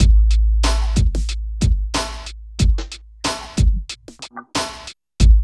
23 DRUM LP-L.wav